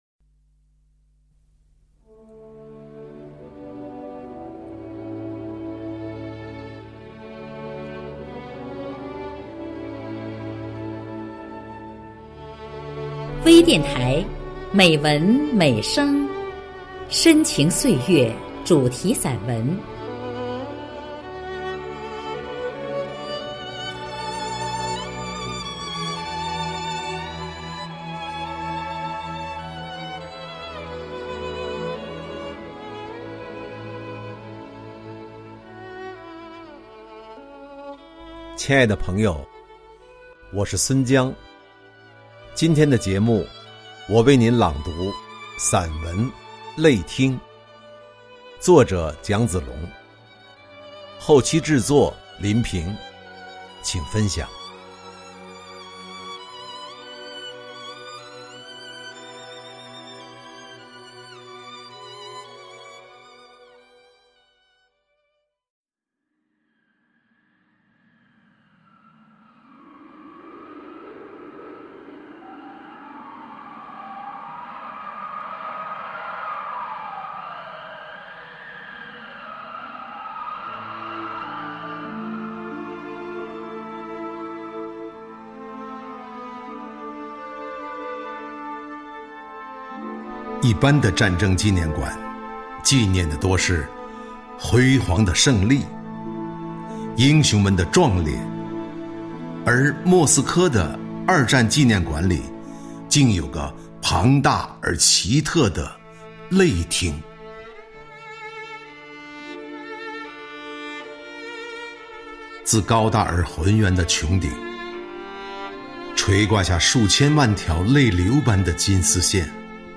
多彩美文  专业诵读
朗 诵 者